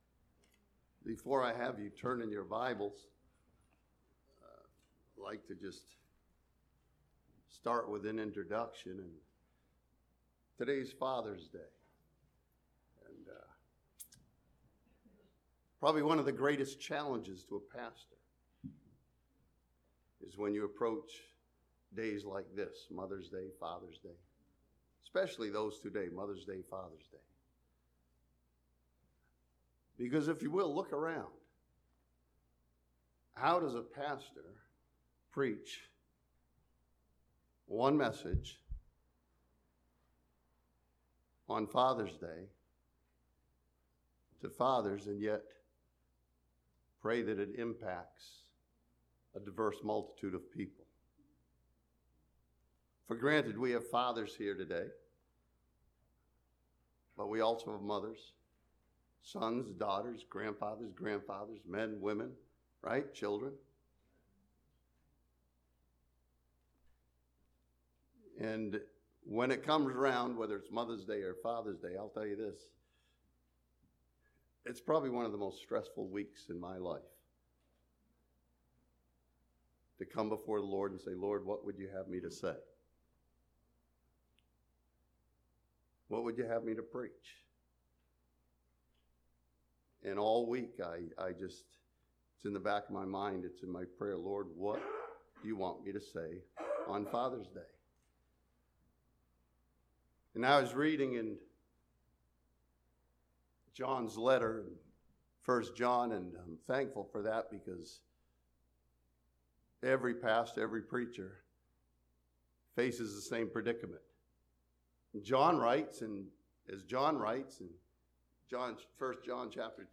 This Father's Day message from Genesis chapter 5 studies the godly example of Enoch who walked with God.